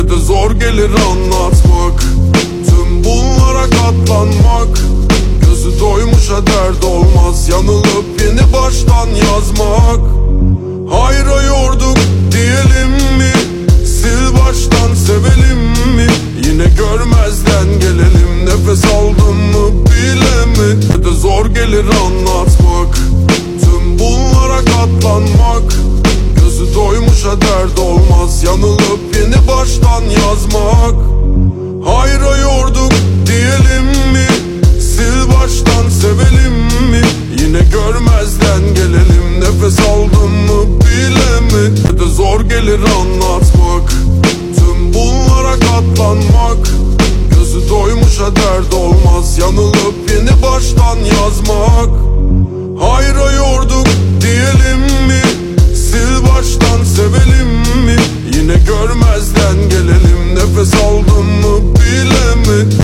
Жанр: Jazzdauren